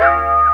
EP BELL.wav